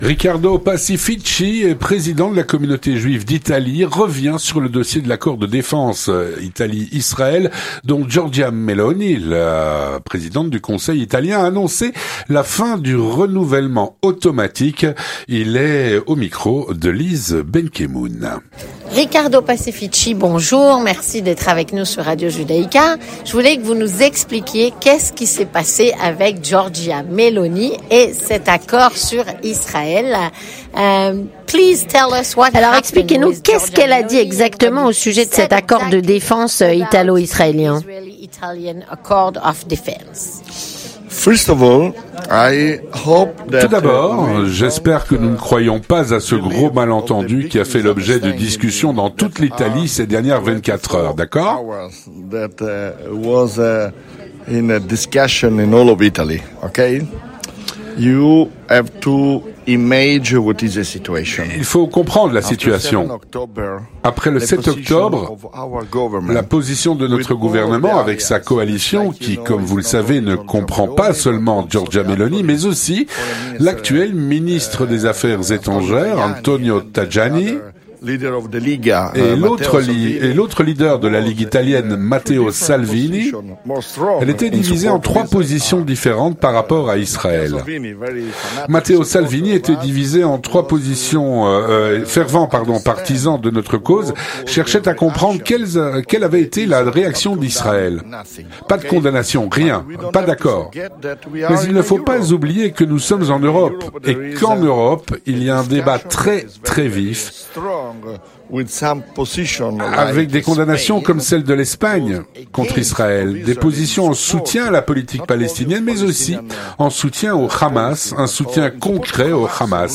Traduit et doublé